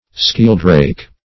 Search Result for " skieldrake" : The Collaborative International Dictionary of English v.0.48: Skeldrake \Skel"drake`\, or Skieldrake \Skiel"drake`\, n. (Zool.) (a) The common European sheldrake.